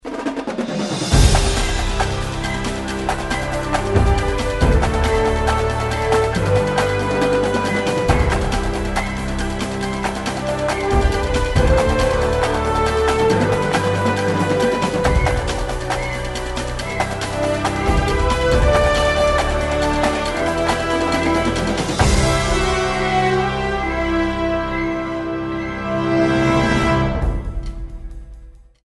Recorded at Sony Pictures Stages